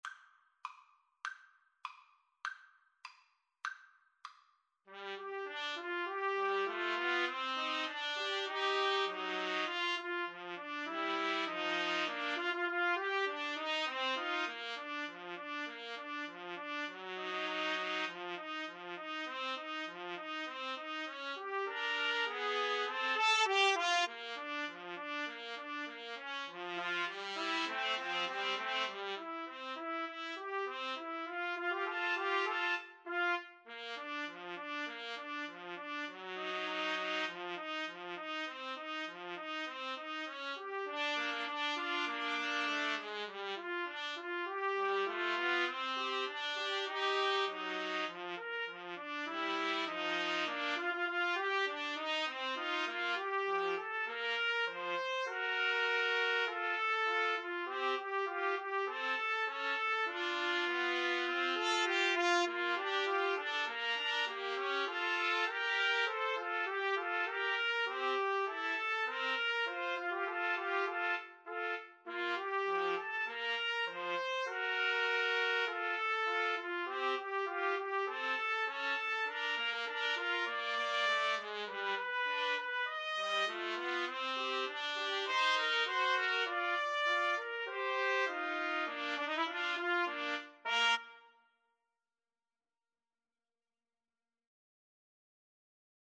Trumpet 1Trumpet 2Trumpet 3
2/4 (View more 2/4 Music)
Tempo di Marcia
Pop (View more Pop Trumpet Trio Music)